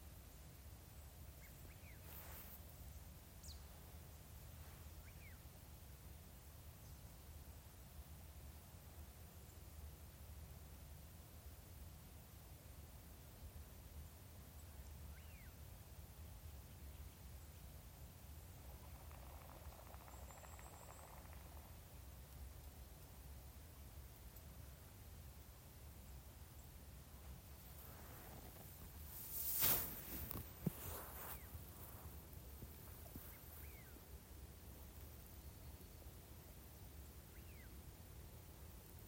Putni -> Pīles ->
Baltvēderis, Mareca penelope
StatussDzirdēta balss, saucieni